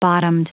Prononciation du mot bottomed en anglais (fichier audio)
Prononciation du mot : bottomed